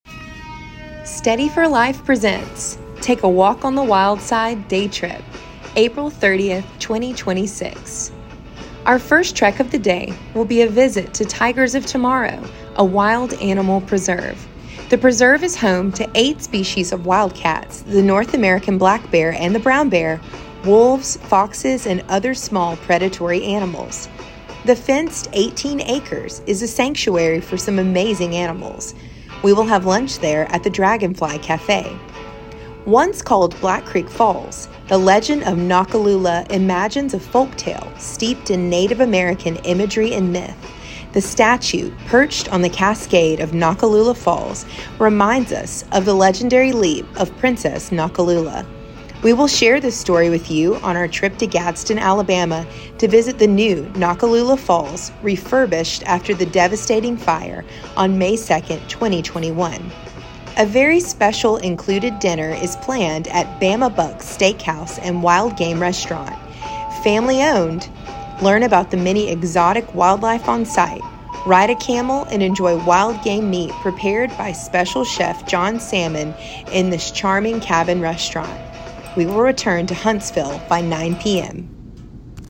2.-Apr-24-Take-a-Walk-on-the-Wild-Side-Day-Trip-with-music.mp3